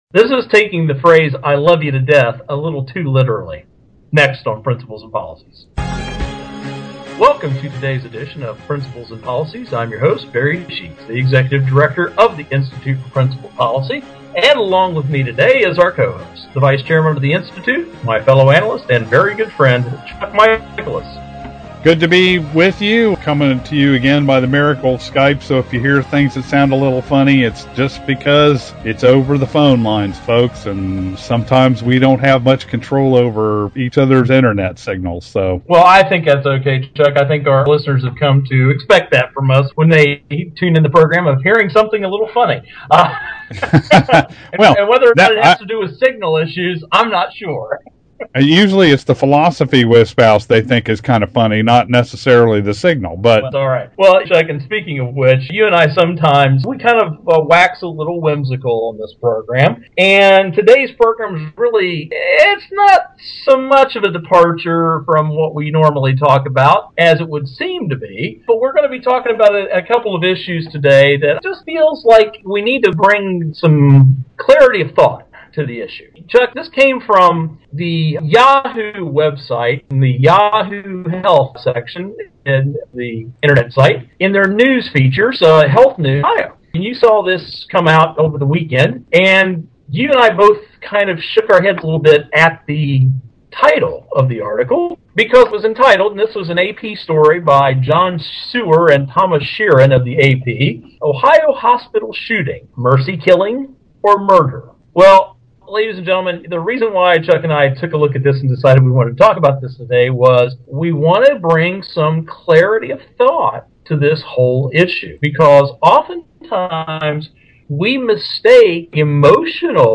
Our Principles and Policies radio show for Monday June 18, 2012.